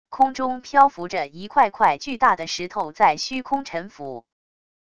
空中漂浮着一块块巨大的石头在虚空沉浮wav音频